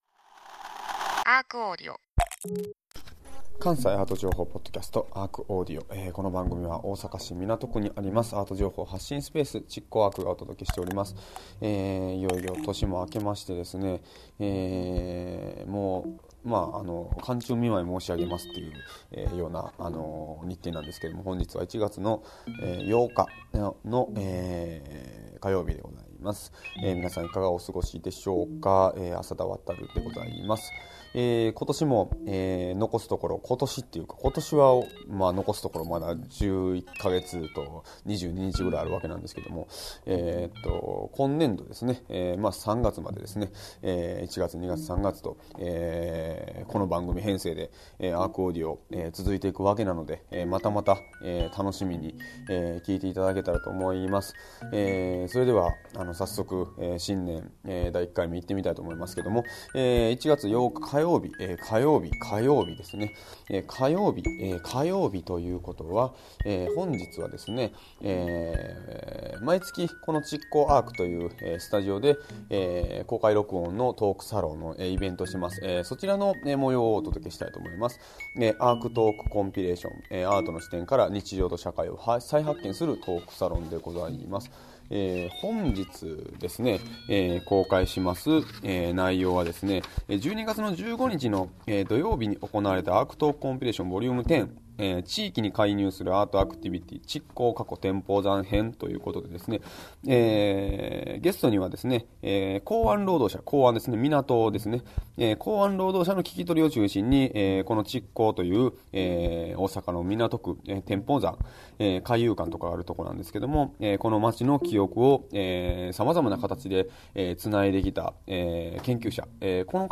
毎週火曜日は築港ARCにて毎月開催されるARCトークコンピレーションの模様を全3回に分けてお届けします。